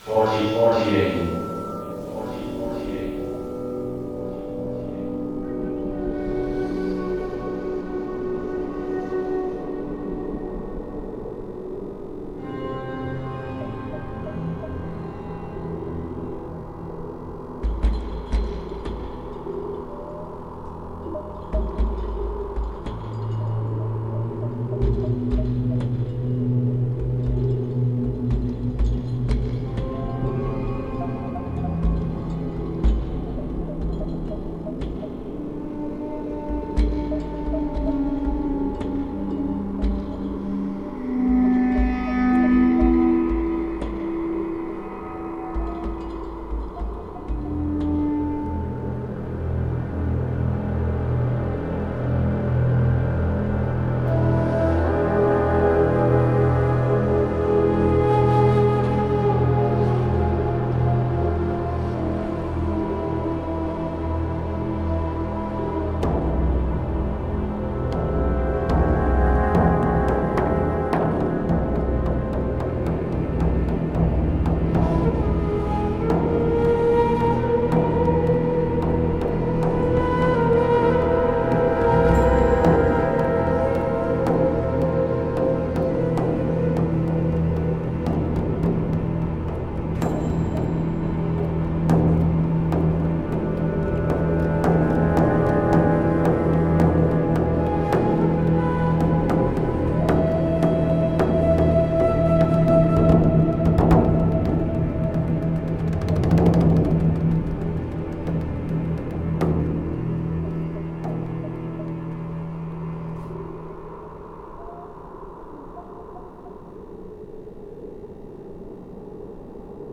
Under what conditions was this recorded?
But, I made a field recording of my experience (points) to share. It should probably be noted that these sounds are recorded from me playing World of Warcraft, and so probably also remain the property of Blizzard.